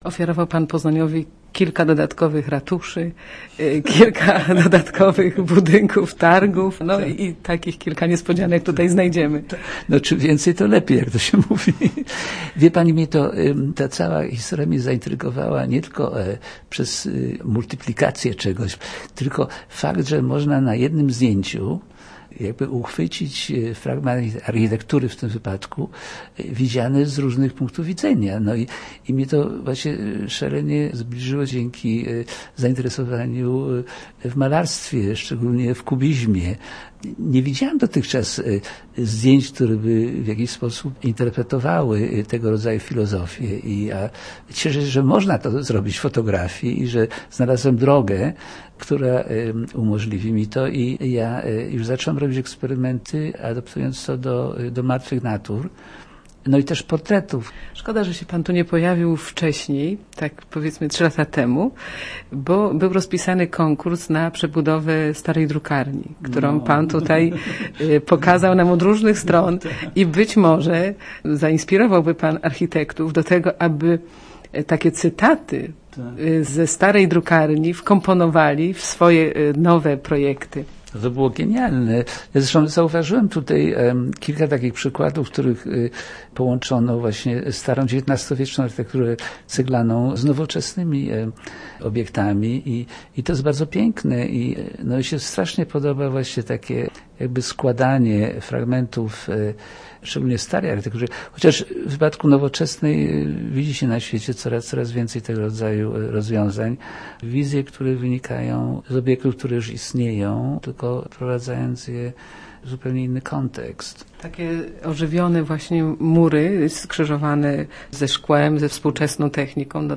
r9uu7p7oiat1t4q_rozmowa_horowitz.mp3